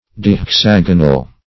Search Result for " dihexagonal" : The Collaborative International Dictionary of English v.0.48: Dihexagonal \Di`hex*ag"o*nal\, a. [Pref. di- + hexagonal.]
dihexagonal.mp3